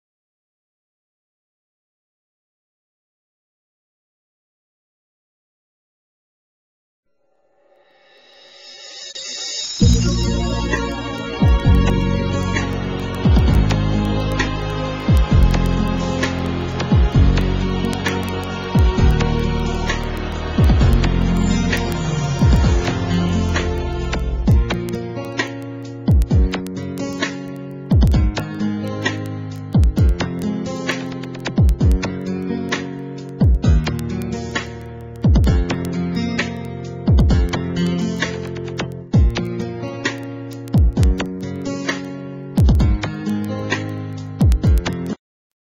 NOTE: Background Tracks 1 Thru 8